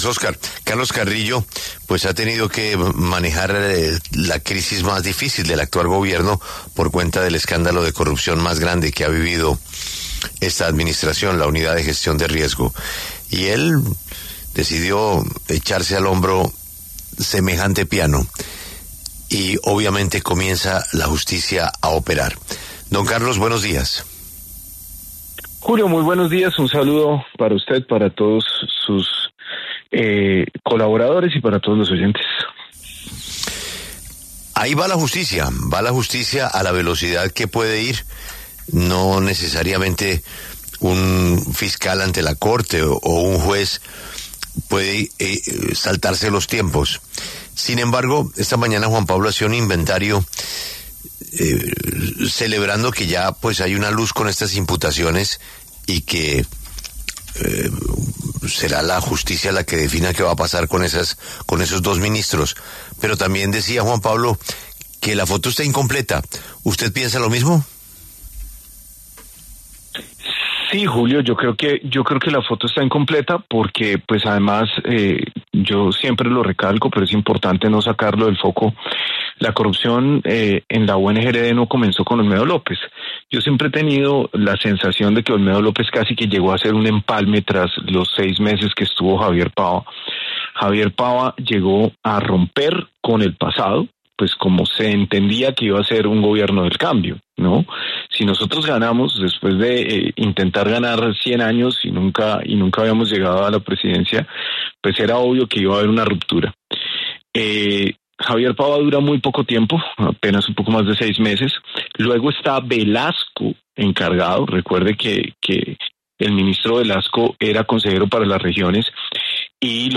El director de la Unidad para la Gestión de Riesgos de Desastres habló en la W sobre la imputación de cargos a los exministros involucrados en el escándalo de la entidad.